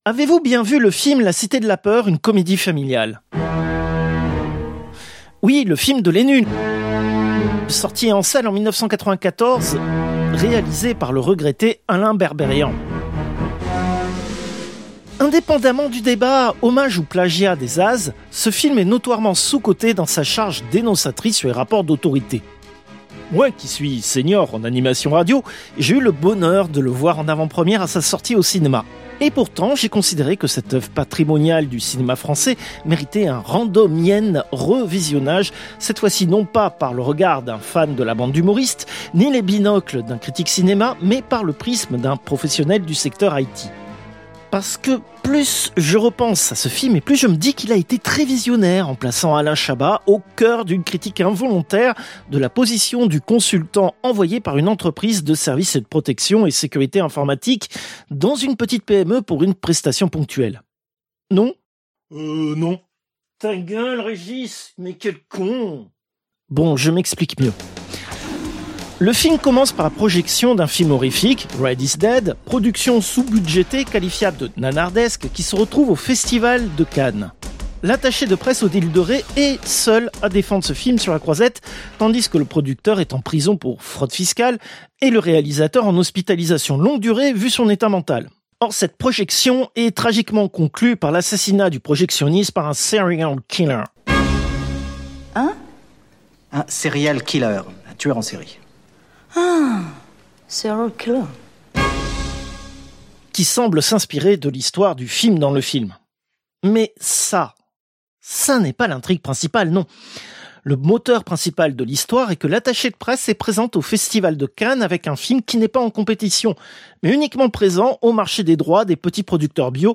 Extrait de l'émission CPU release Ex0227 : lost + found (un quart null).